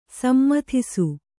♪ sammathisu